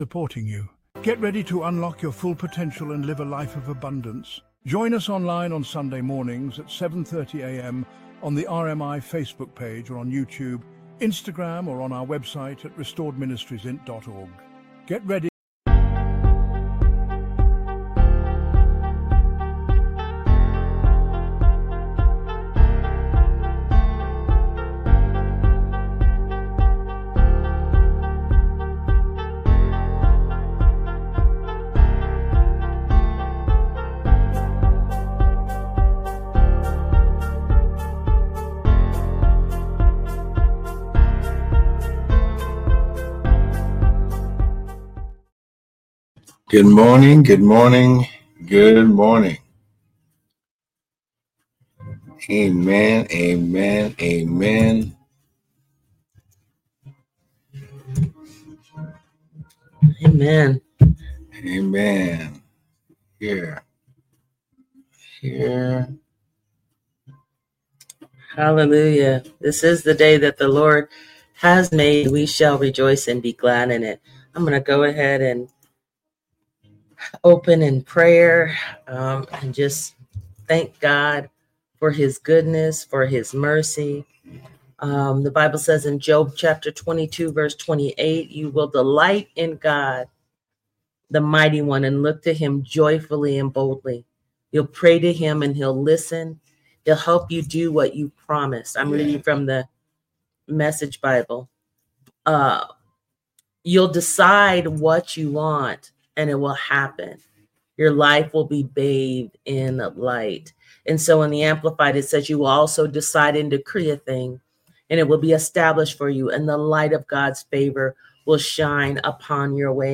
1 Predigt